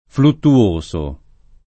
fluttuoso [ fluttu- 1S o ] agg.